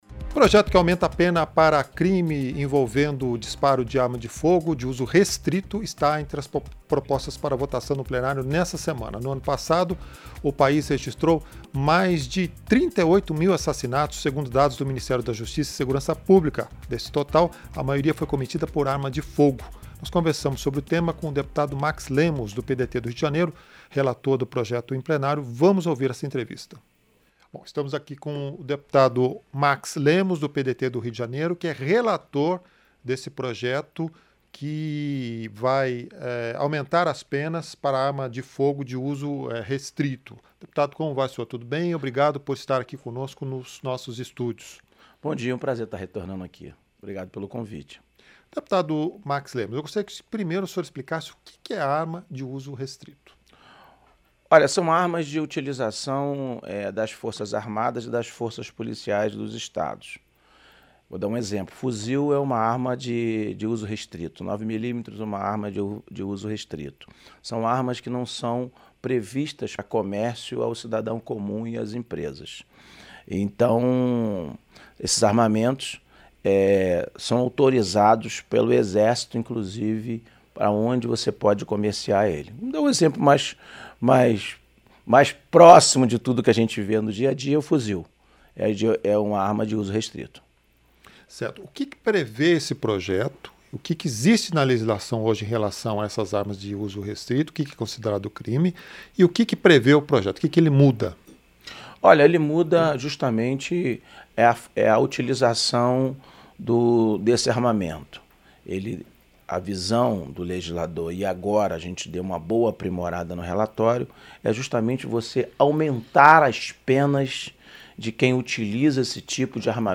Entrevista -Dep. Max Lemos (PDT-RJ)